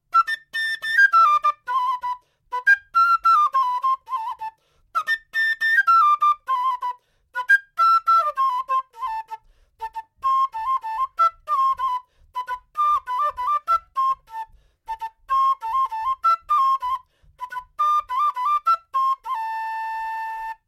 Quena
Así suena una Quena
Es un instrumento de viento, tradicionalmente hecho en madera o caña.
quena.wav Audio del al son andio